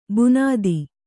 ♪ bunādi